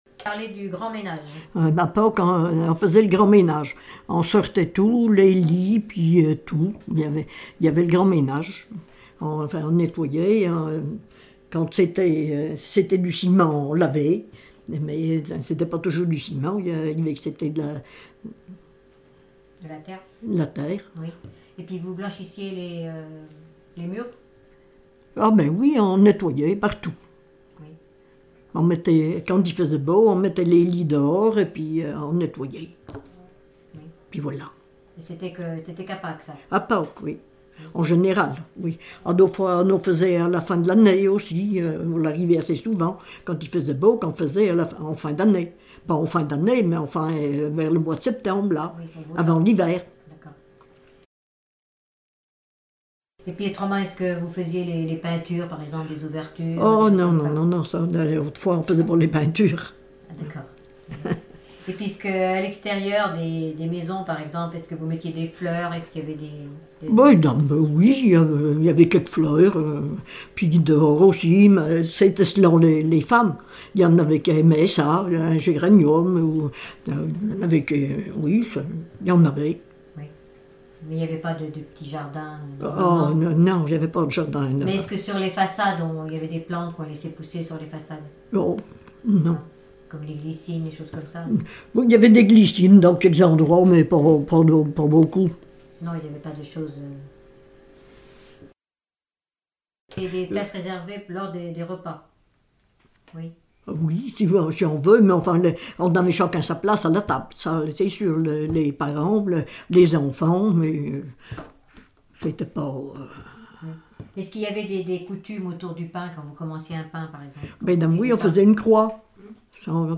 Chansons et témoignages
Catégorie Témoignage